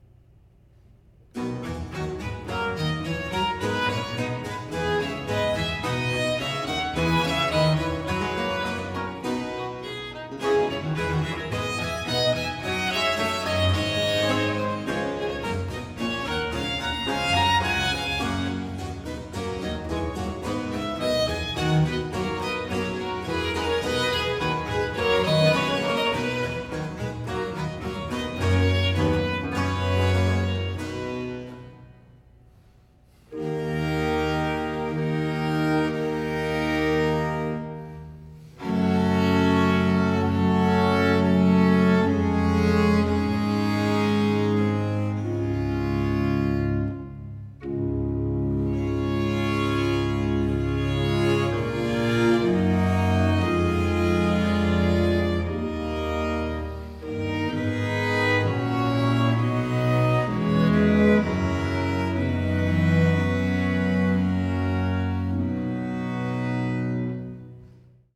• Genres: Baroque, Chamber Music
Baroque string ensemble